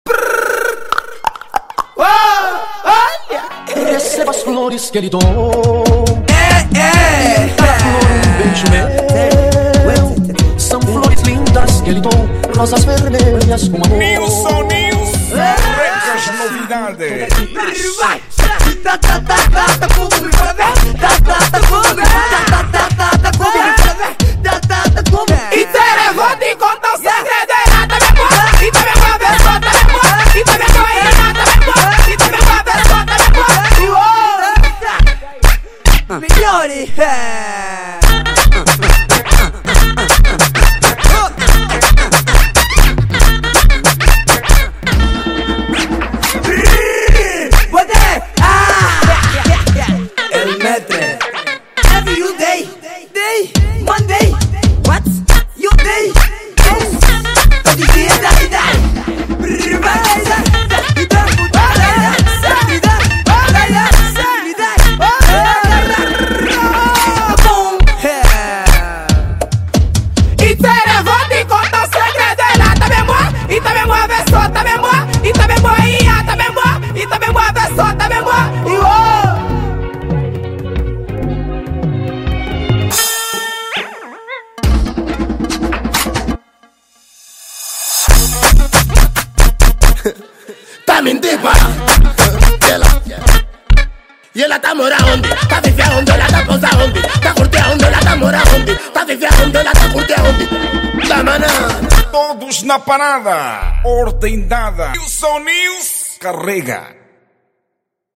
| Afro Music